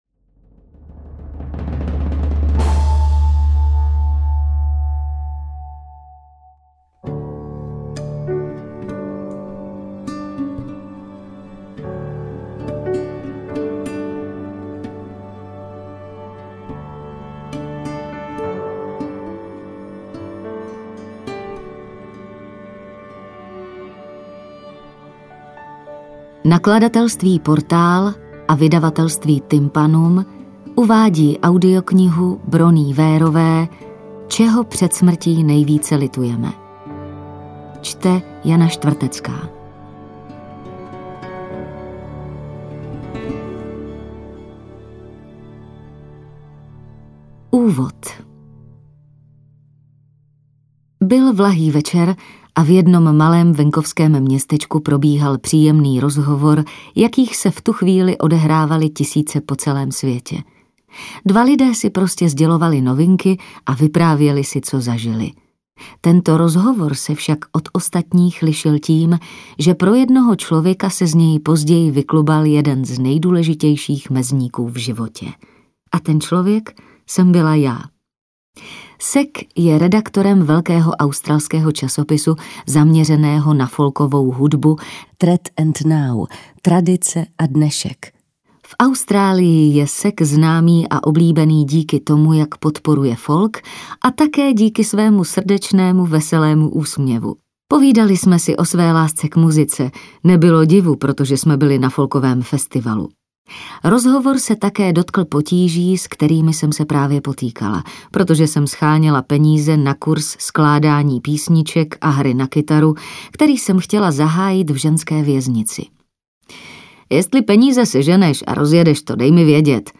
Audiokniha čerpá z osobní zkušenosti autorky, která několik let působila v paliativní péči. Setkání s lidmi, jimž perspektiva blízké smrti naléhavě otevřela palčivá osobní témata a možná trochu pozapomenuté hodnoty, proměnila i její vlastní život.